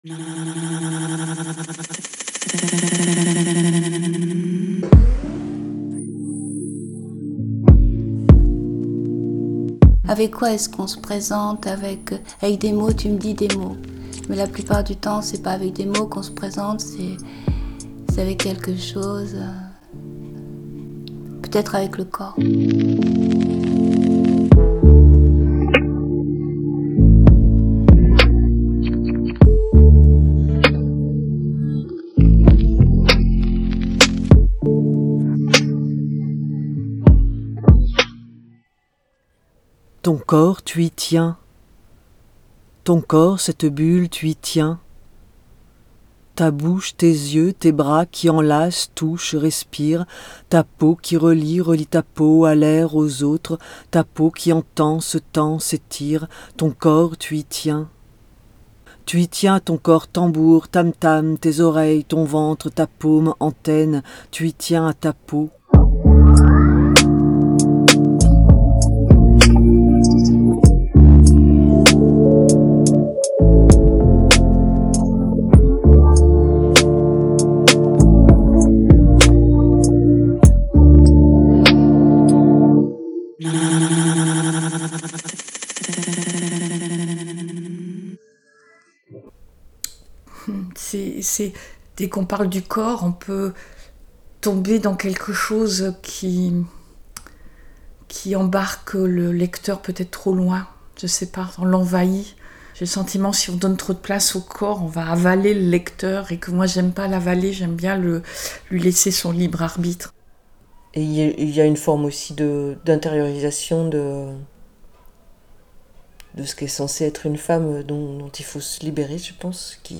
En poésie comme ailleurs les femmes ont longtemps été dépossédées de leurs images, de leurs corps et de son expression. Dans ce documentaire polyphonique, sept poétesses d’aujourd’hui évoquent la place du corps dans leurs œuvres.